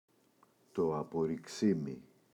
απορριξίμι, το [apori’ksimi] – ΔΠΗ